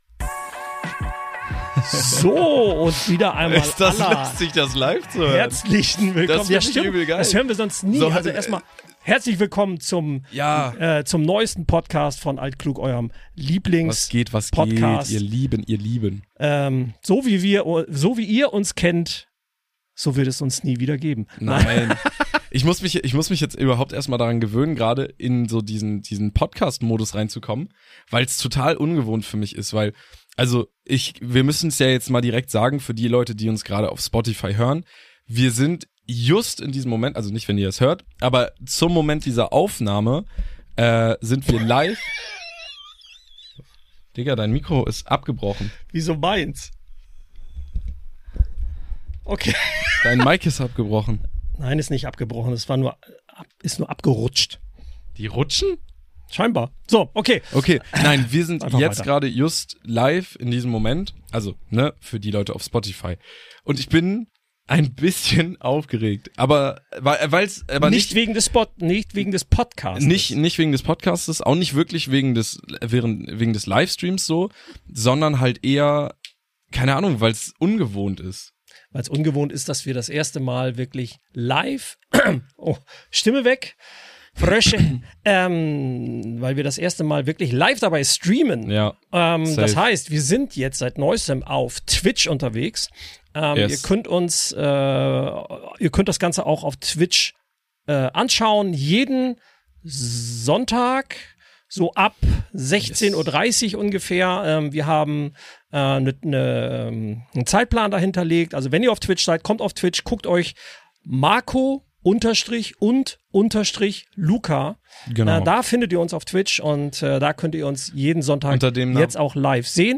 Unser ERSTER Live Podcast!!